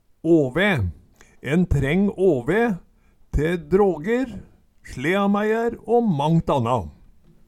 Høyr på uttala Ordklasse: Substantiv hankjønn Kategori: Reiskap og arbeidsutstyr Attende til søk